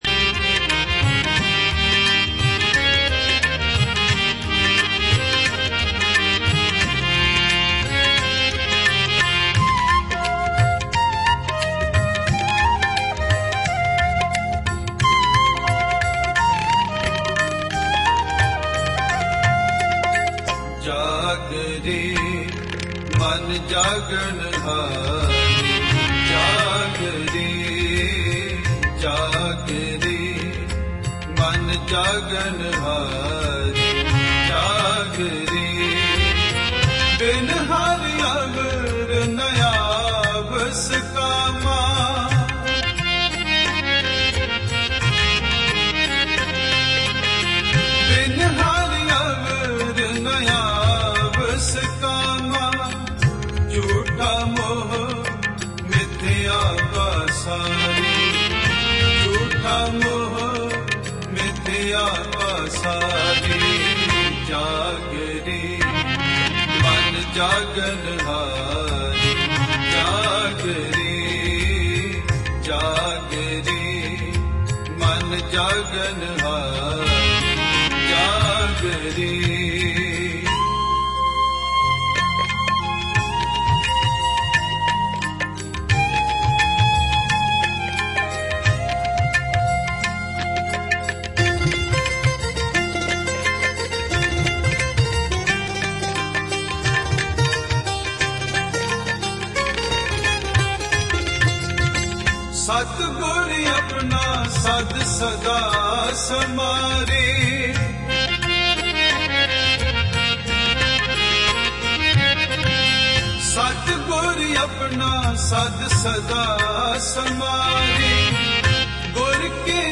Gurbani Shabad Kirtan